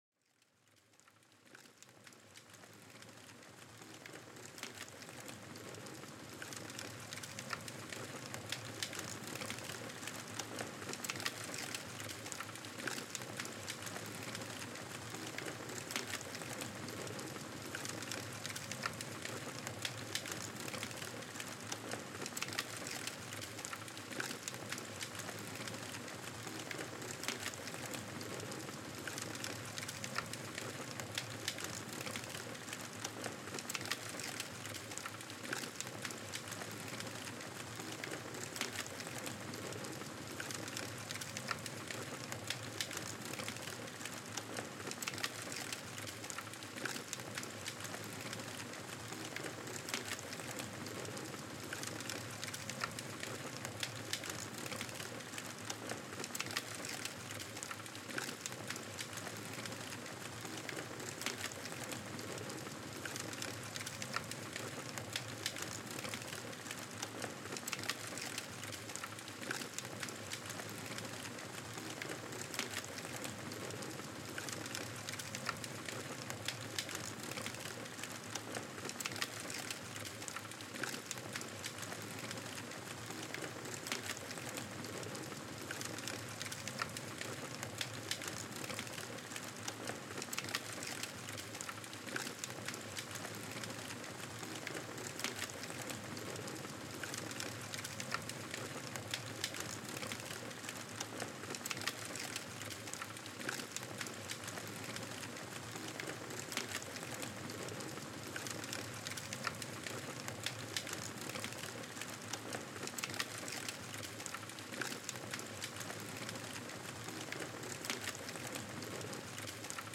Plongez dans l'ambiance apaisante des crépitements d’un feu de camp sous un ciel étoilé.Laissez chaque flamme crépiter doucement, emportant avec elle vos tensions du jour.Une expérience parfaite pour apaiser l'esprit et se préparer à un sommeil profond.Ce podcast vous emmène au cœur des sons de la nature, un refuge sonore pour l'esprit.Des paysages sonores conçus pour favoriser la relaxation, la méditation et le repos.Laissez les murmures de la nature vous guider vers un sommeil paisible.